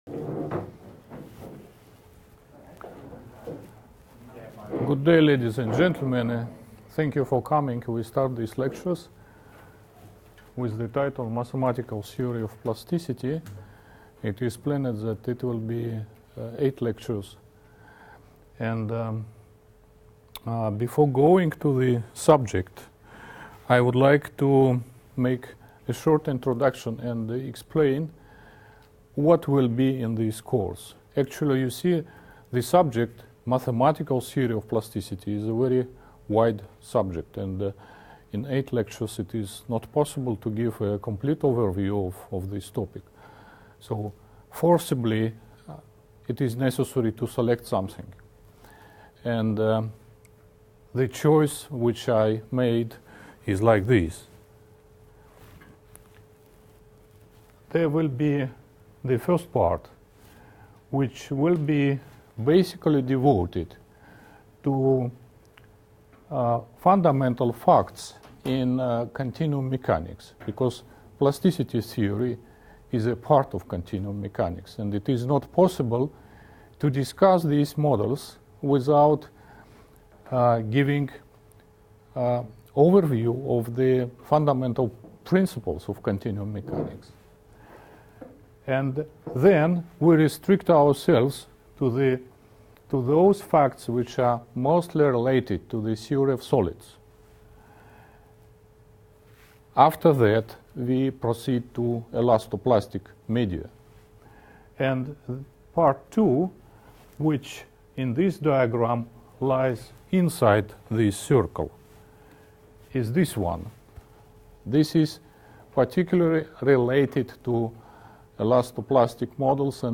lecture series on mathematical theory of plasticity